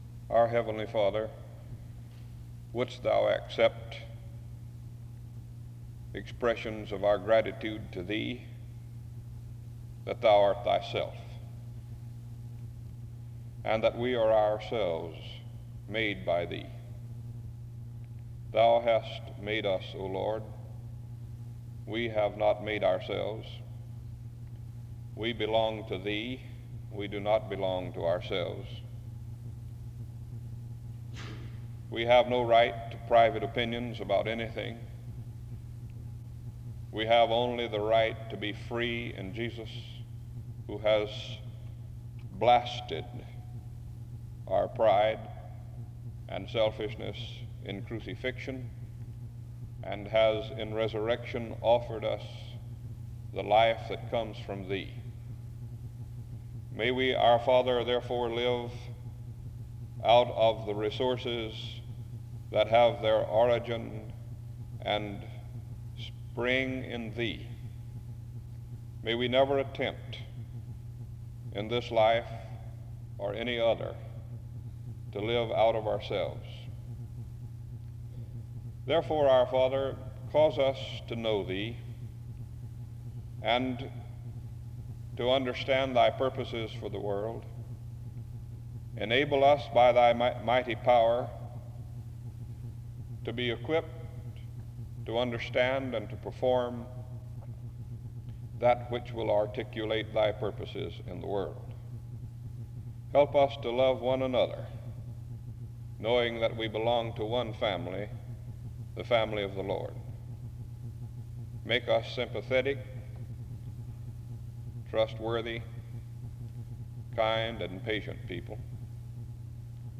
Download .mp3 Description After a prayer and introduction (start-5:12)
SEBTS Chapel and Special Event Recordings SEBTS Chapel and Special Event Recordings